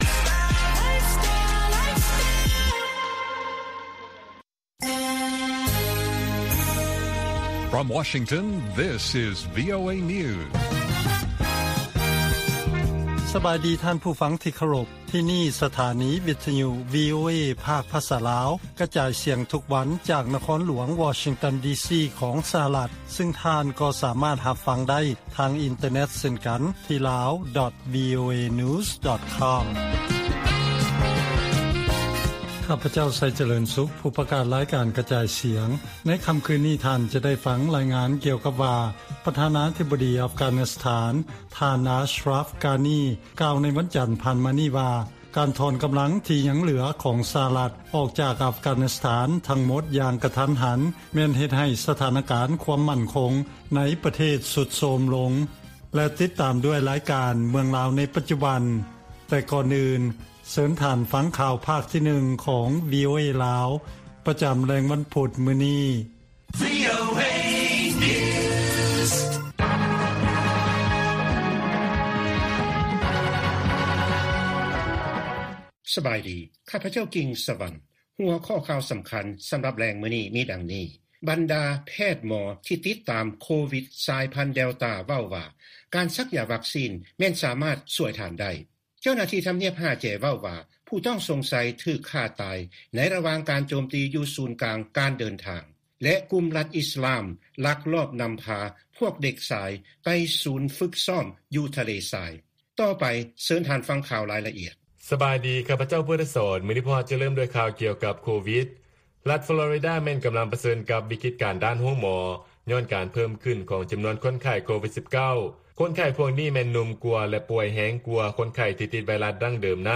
ລາຍການກະຈາຍສຽງຂອງວີໂອເອ ລາວ: ກຸ່ມລັດອິສລາມ ລັກລອບນຳພາ ພວກເດັກຊາຍ ໄປສູນຝຶກຊ້ອມ ຢູ່ທະເລຊາຍ